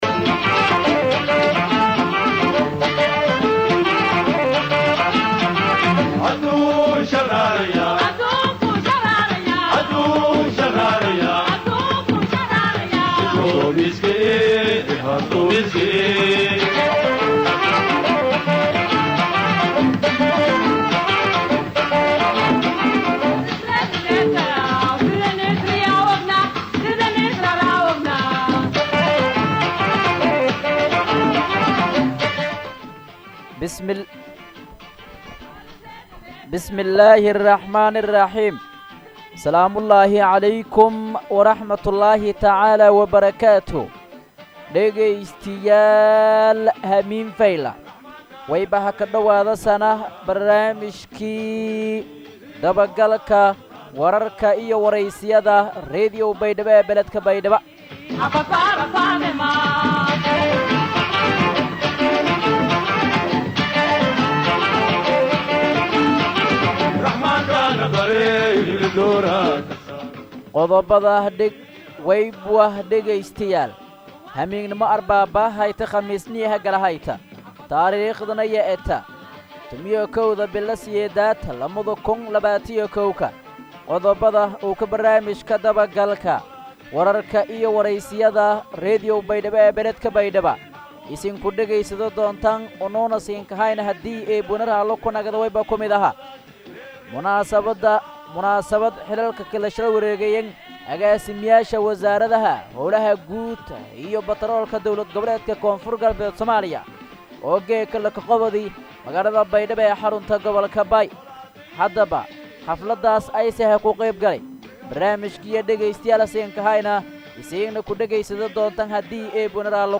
BAYDHABO–BMC:–Dhageystayaasha Radio Baidoa ee ku xiran Website-ka Idaacada Waxaan halkaan ugu soo gudbineynaa Barnaamijka Dabagalka Wararka iyo Wareysiyada ee ka baxay Radio Baidoa.